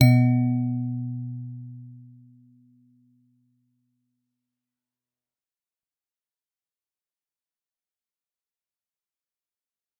G_Musicbox-B2-f.wav